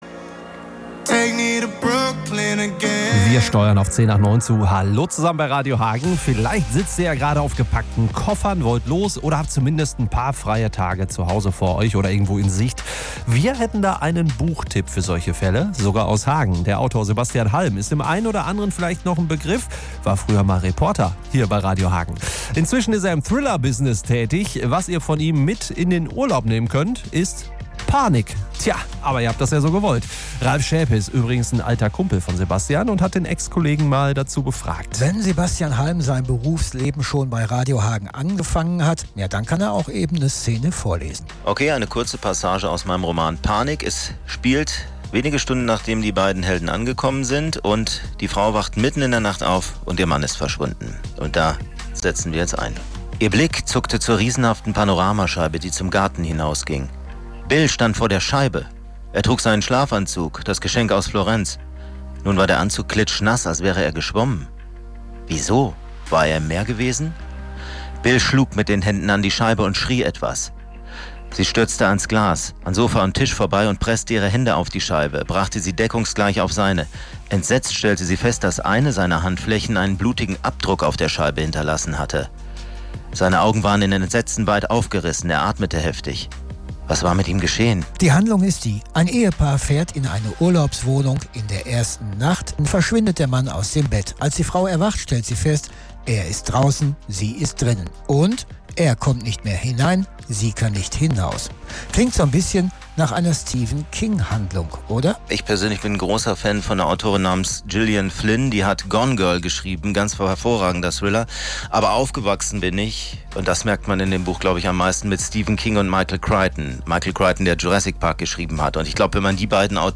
MITSCHNITT AUS DER SENDUNG